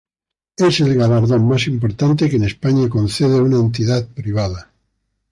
ga‧lar‧dón
/ɡalaɾˈdon/